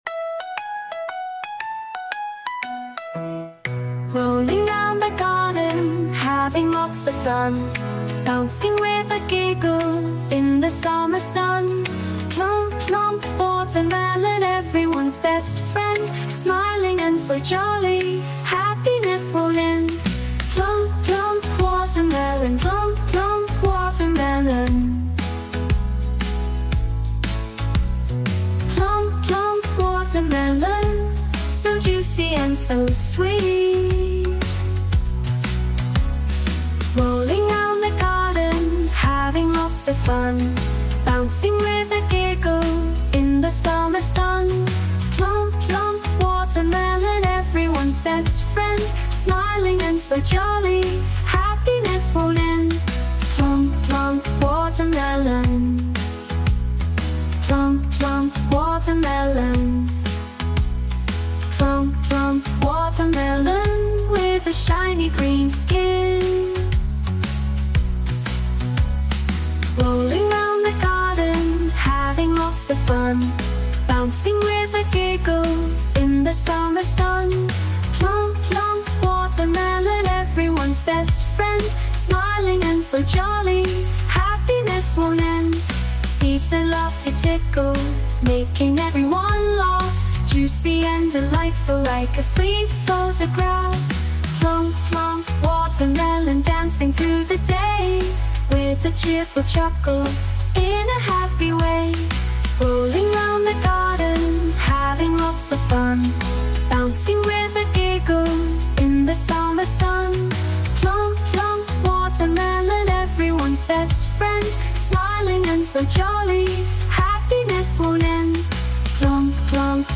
Nursery Rhymes & Kids Songs